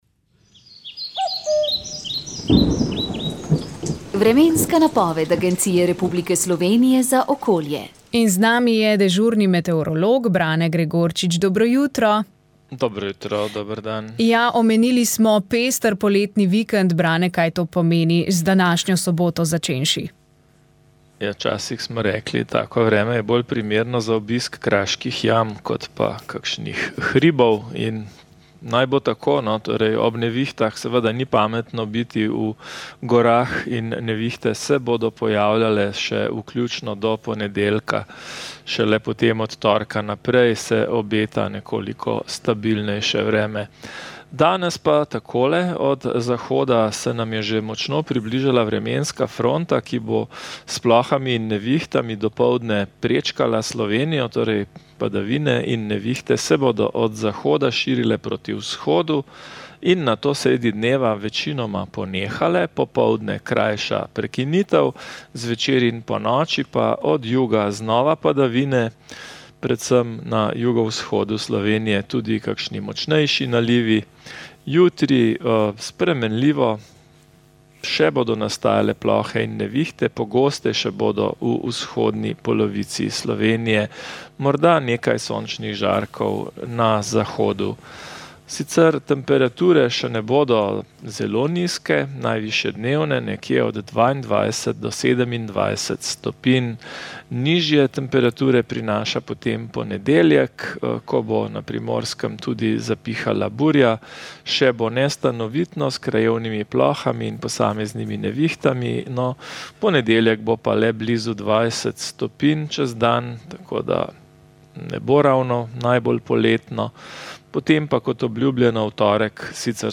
Vremenska napoved 24. julij 2025